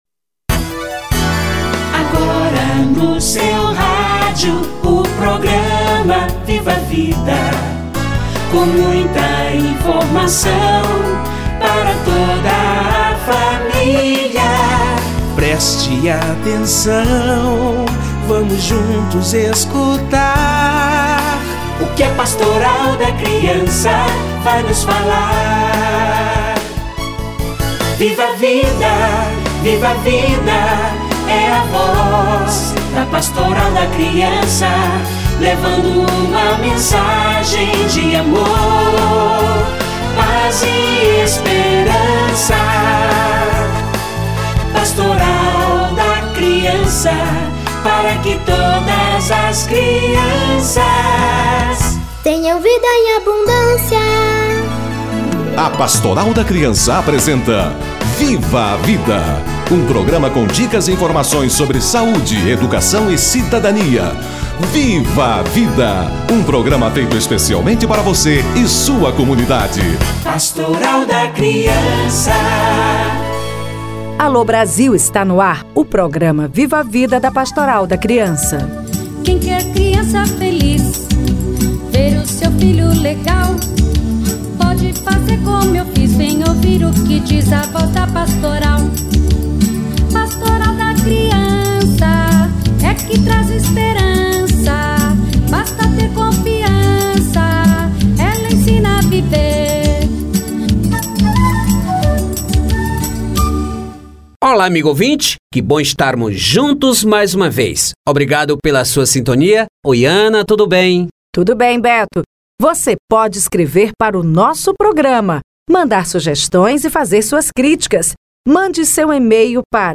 Crianças e atitudes construtivas - Entrevista